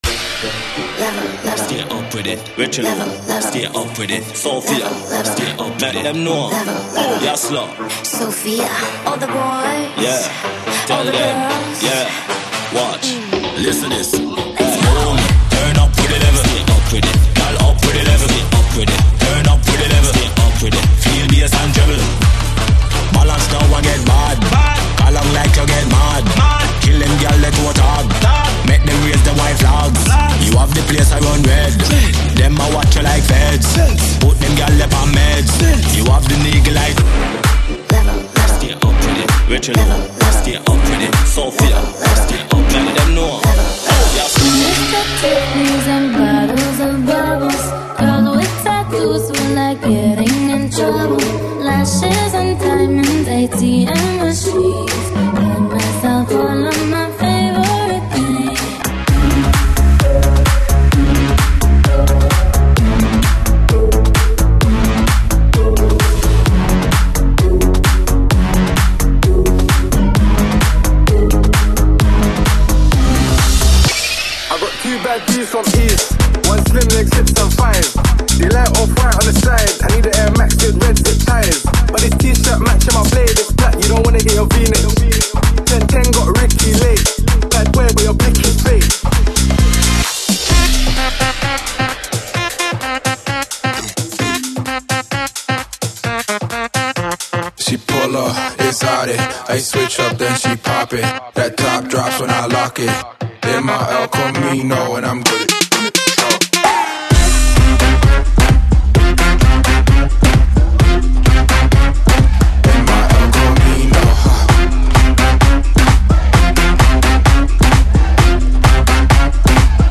FORMAT: 32COUNT